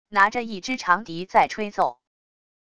拿着一只长笛在吹奏wav音频